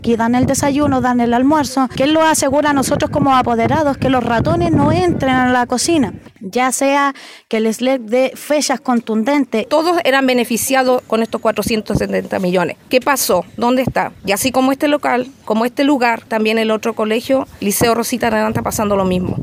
apoderadas-1-x-liceos.mp3